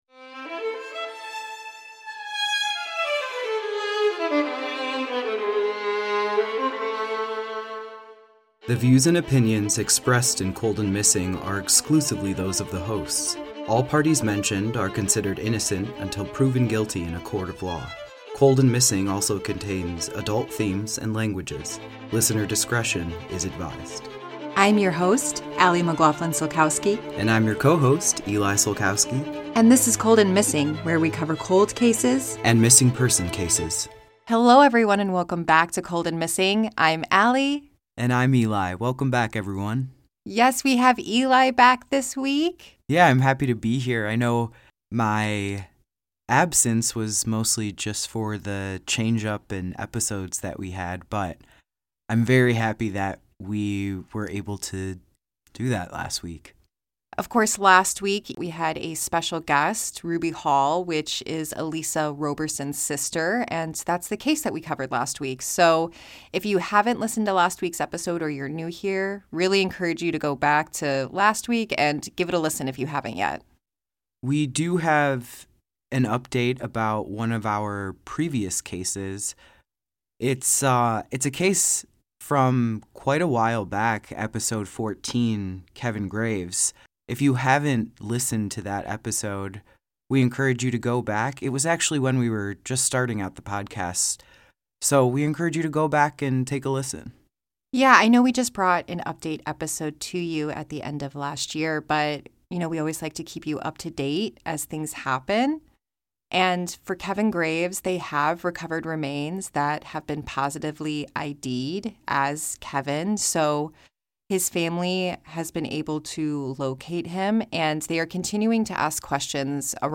a husband and wife duo- will bring you either a Cold Case or a unresolved missing person.